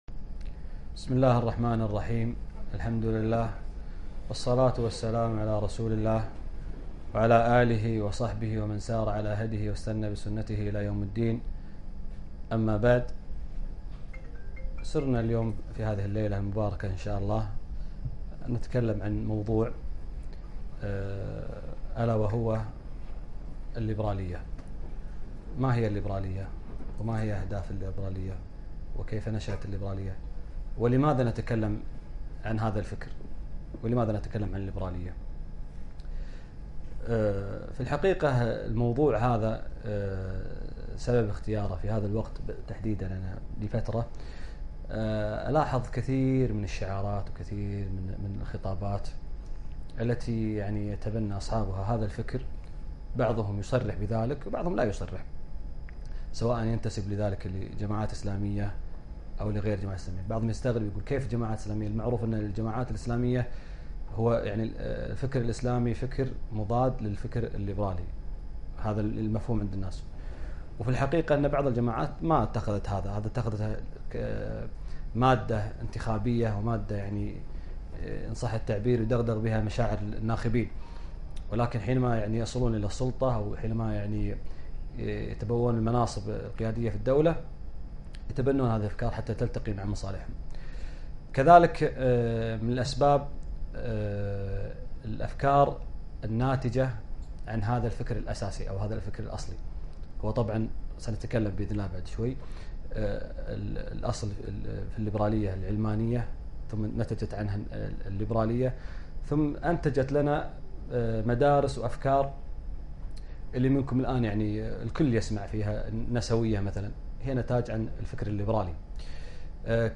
محاضرة - [ وقــفـات مع الليـبـرالـيـــة وبـعـض أهـدافــهـا ]